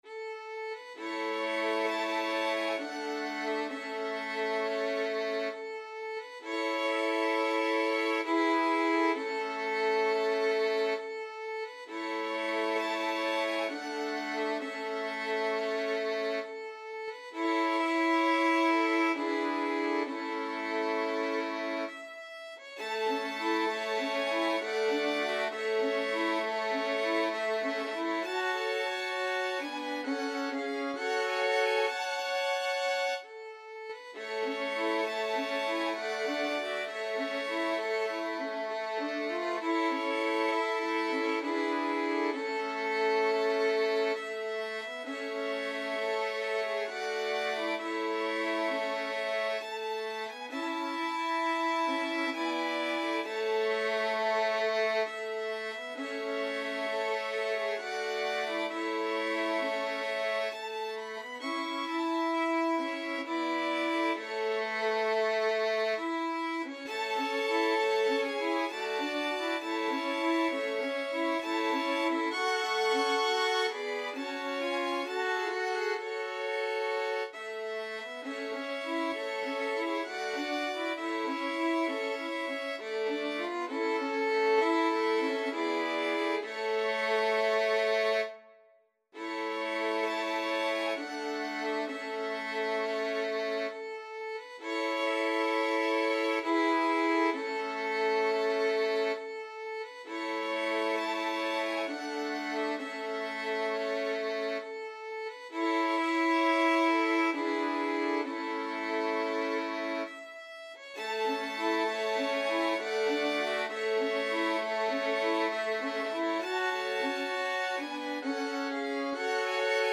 Free Sheet music for Violin Quartet
A major (Sounding Pitch) (View more A major Music for Violin Quartet )
3/4 (View more 3/4 Music)
= 66 Andante
Classical (View more Classical Violin Quartet Music)
last_rose_4VLN.mp3